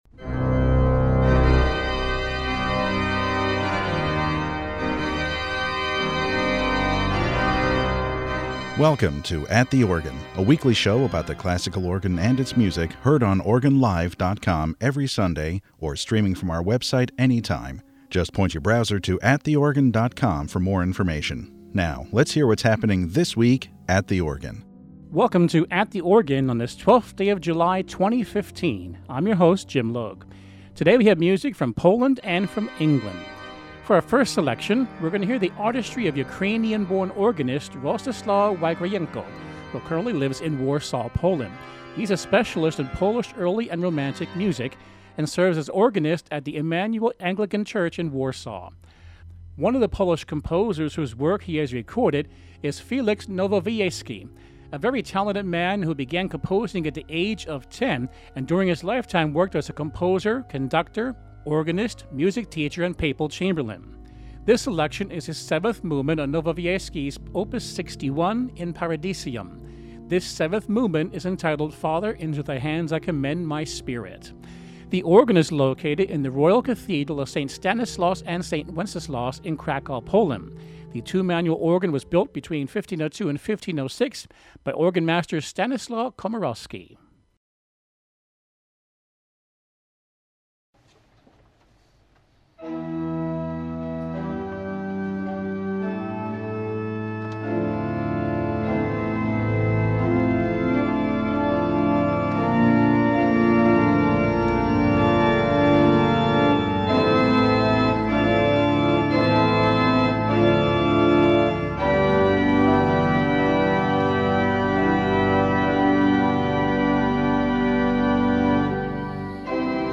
Concerto No 3 in G Major for Organ and Strings